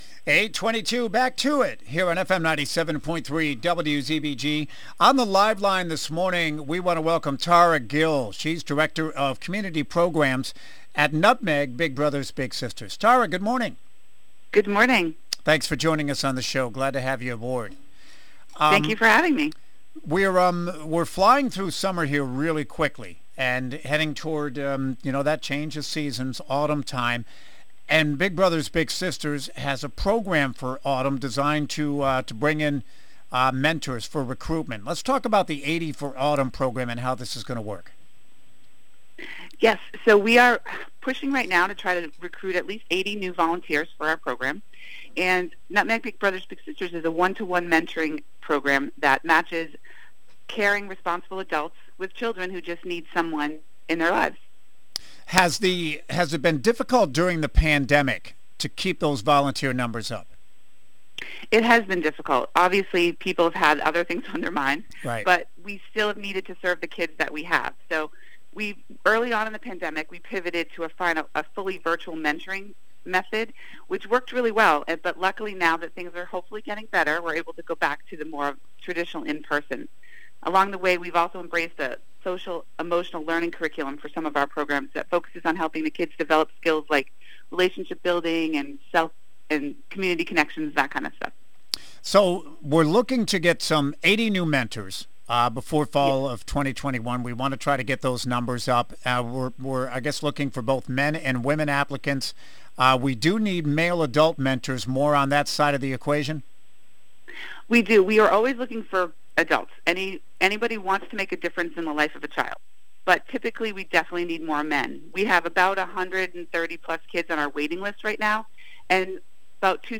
If you missed the interview when it broadcast live, just click on the media player above to hear it now.
NBBBS-August-INTVU.mp3